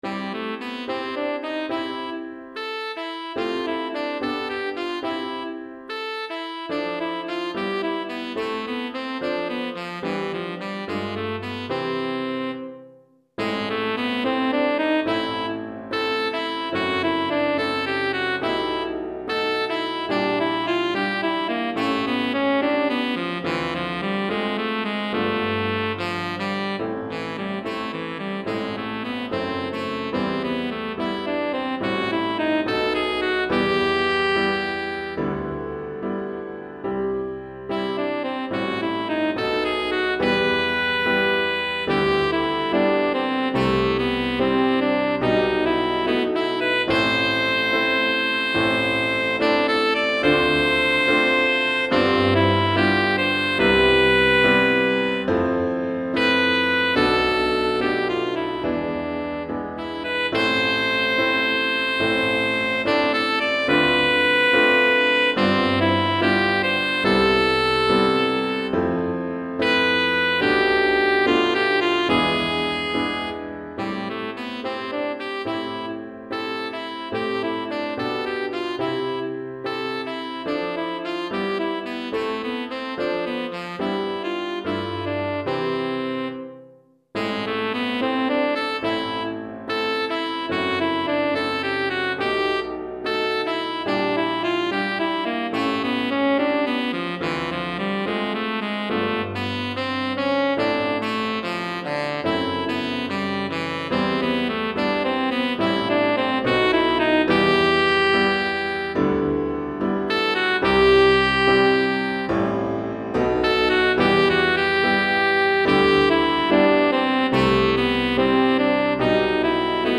Saxophone Ténor et Piano